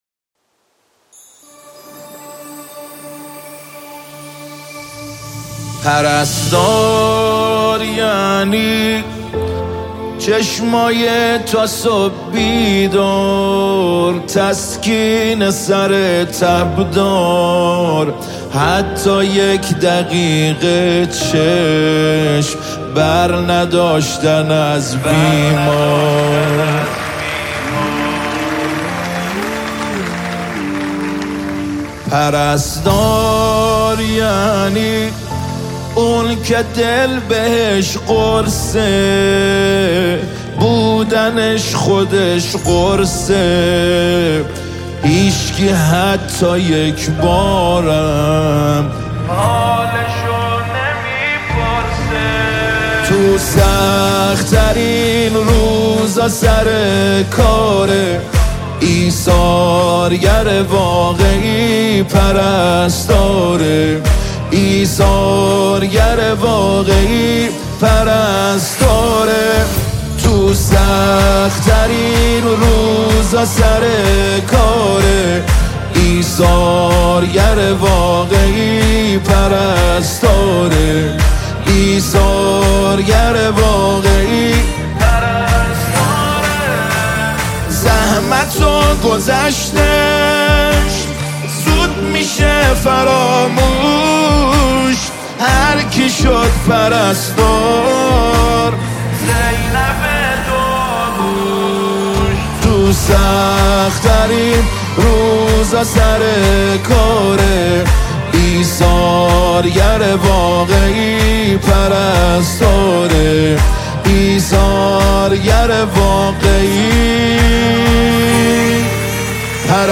ویژه روز پرستار و ولادت حضرت زینب سلام‌ الله‌ علیها